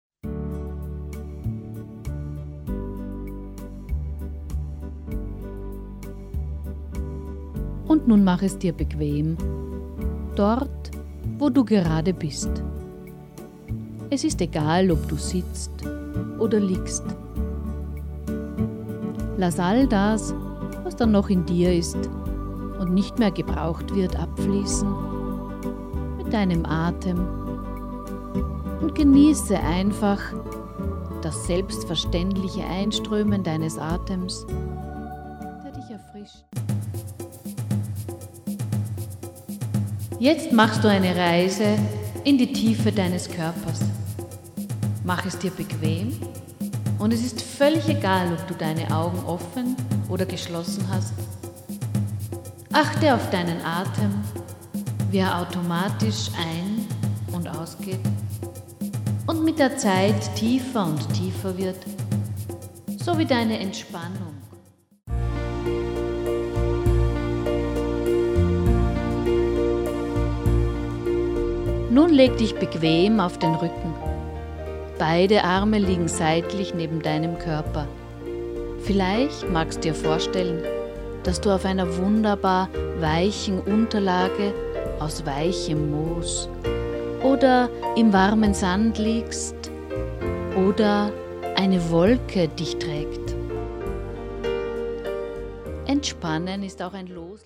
Drei Texte mit Musik unterlegt zum Anhören, Nachdenken, Mitmachen,...